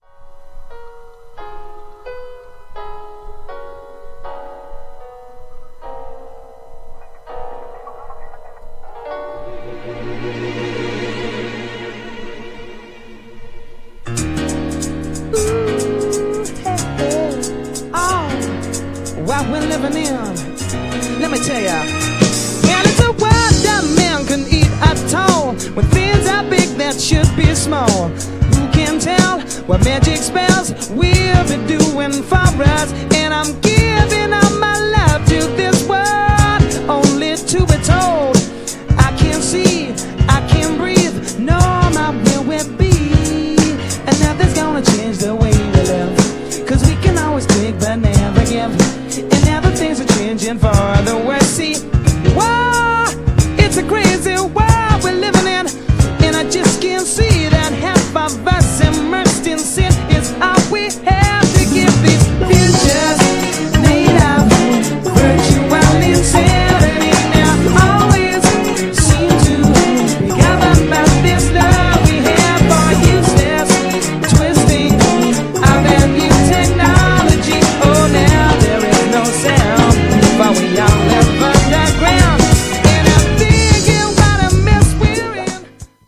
GENRE R&B
BPM 91〜95BPM
90sポップ
JAZZYなR&B
男性VOCAL_R&B